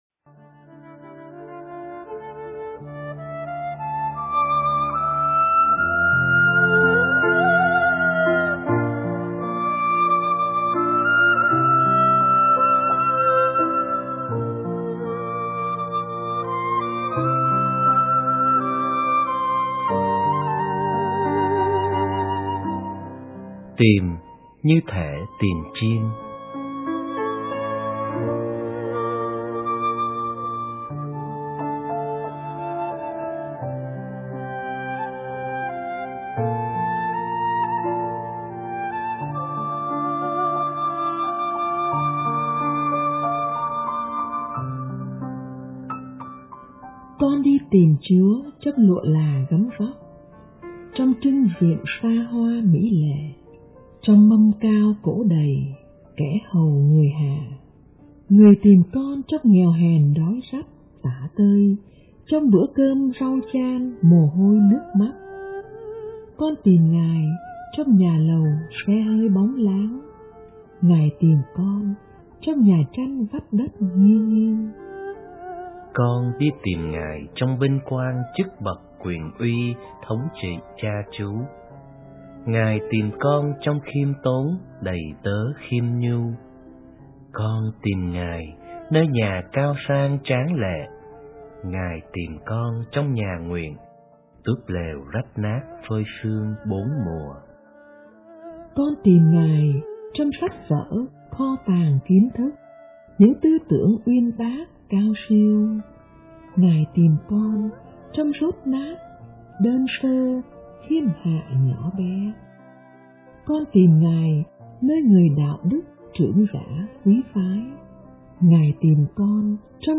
* Thể loại: Suy niệm